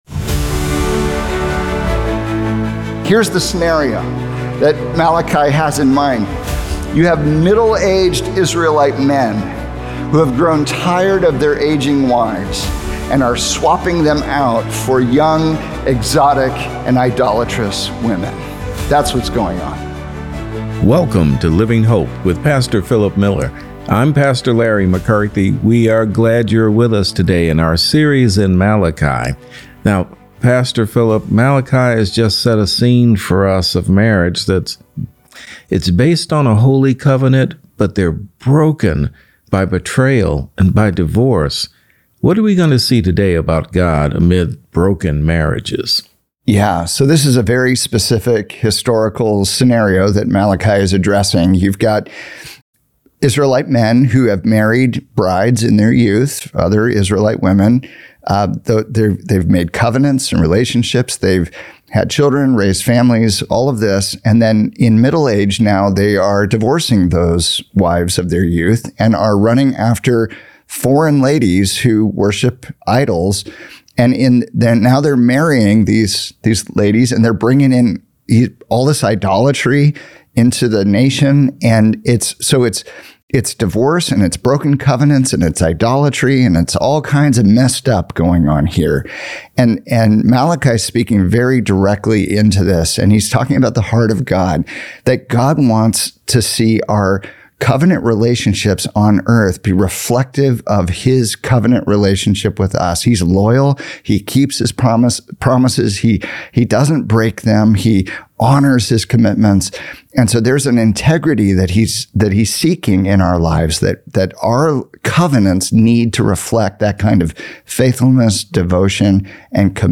Your Broken Relationships Are Blocking Your Prayers | Radio Programs | Living Hope | Moody Church Media